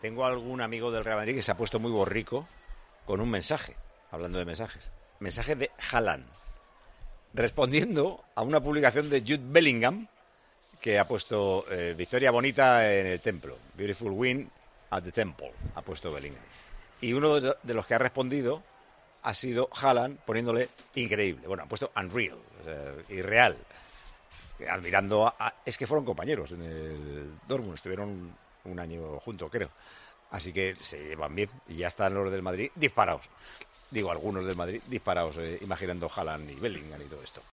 Paco González, director y presentador de Tiempo de Juego, contaba durante el programa de este sábado este hecho y además desvelaba el mensaje que le enviaba algún amigo madridista.